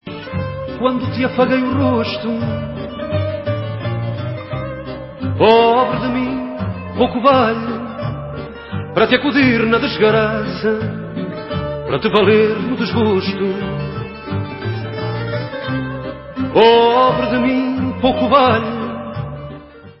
World/Fado